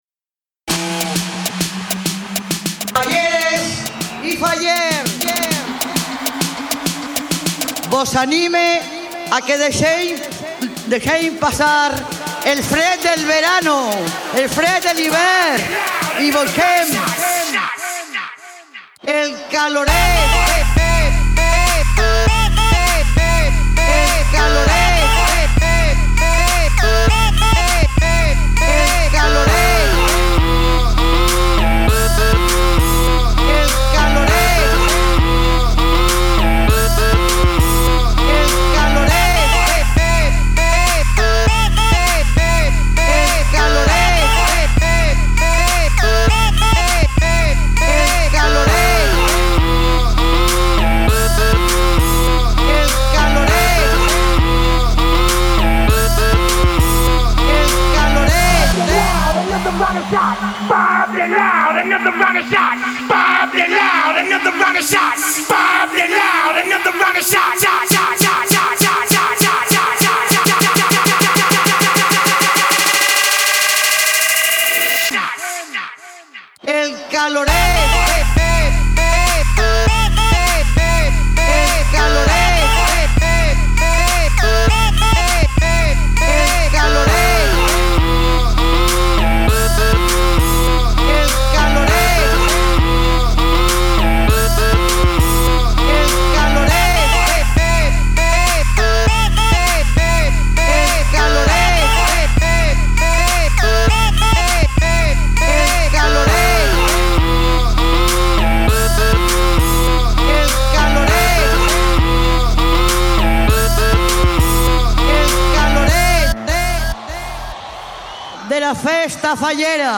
Tu página web de mashups y bootlegs en España y Cataluña